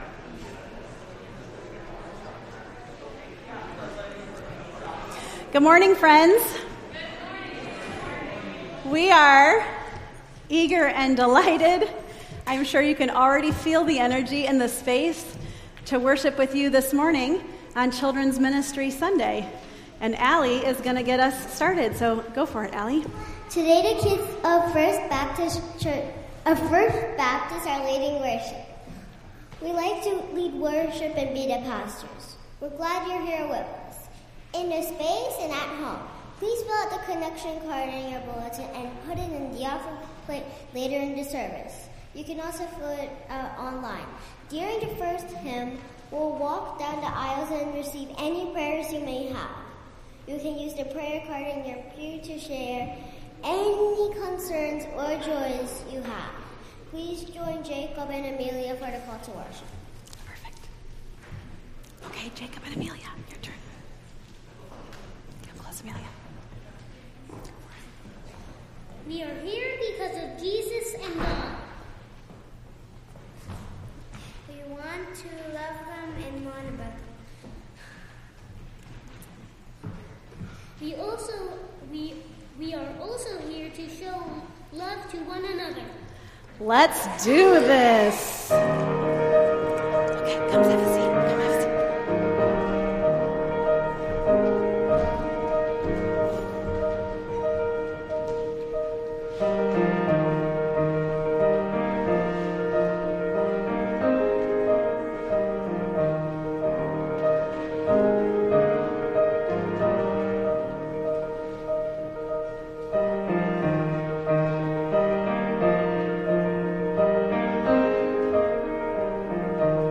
Entire May 18th Service